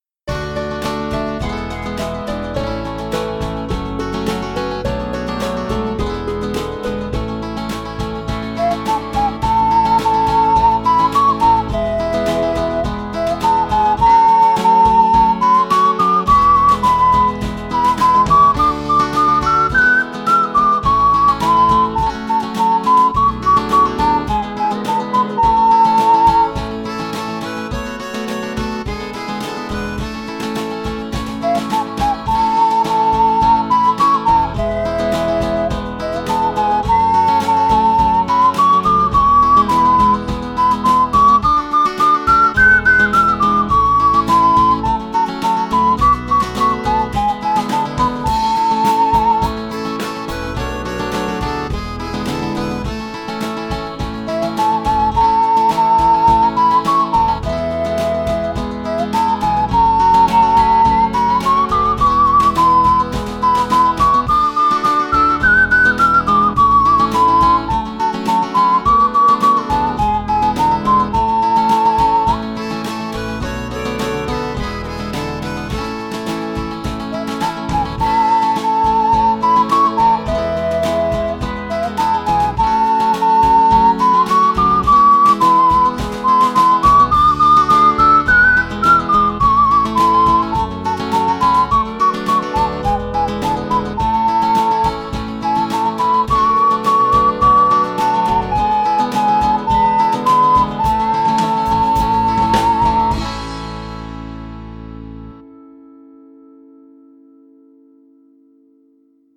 鼻笛練習用音楽素材
鼻笛教室等で使用の練習用素材はこちらにまとめます。
切手のないおくりもの　サンプル演奏付